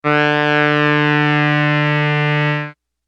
Free MP3 vintage Korg PS3100 loops & sound effects 8